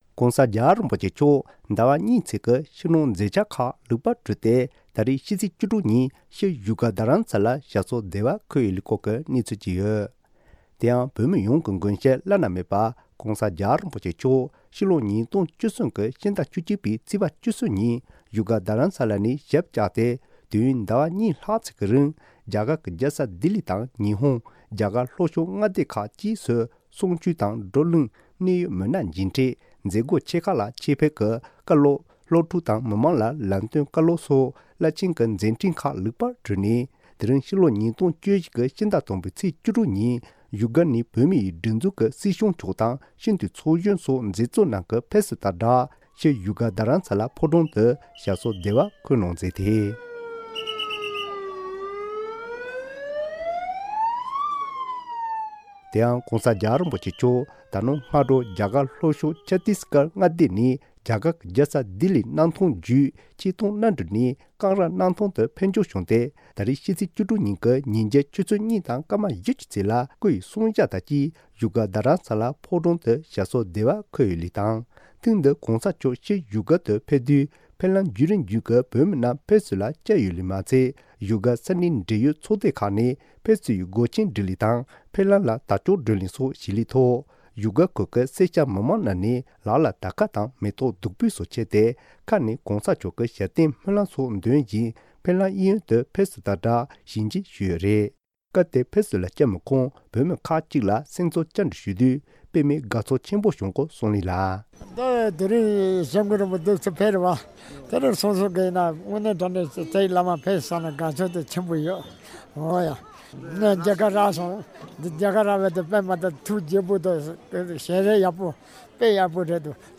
སྒྲ་ལྡན་གསར་འགྱུར། སྒྲ་ཕབ་ལེན།
གསར་འགོད་པ